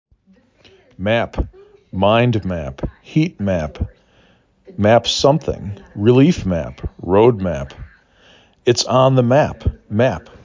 3 Letters, 1 Syllable
3 Phonemes
m a p